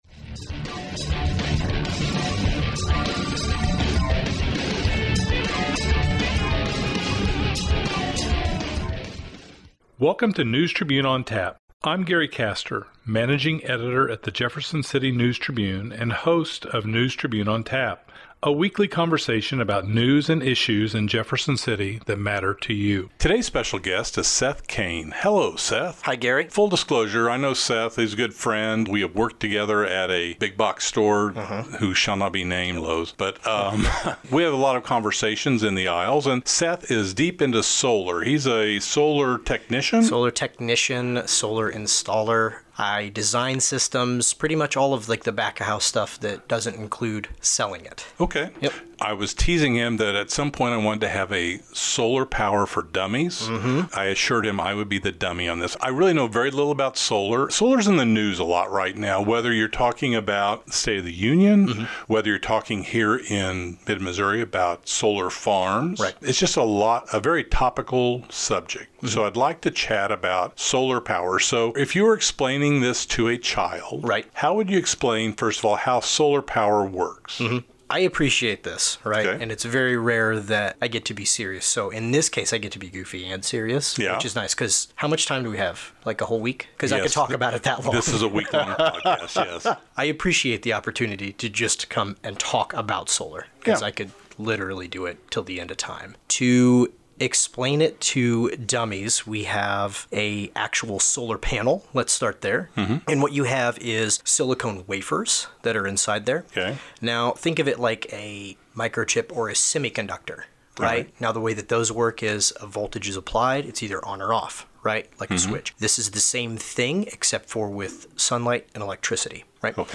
chats with solar technician